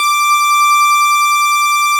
snes_synth_074.wav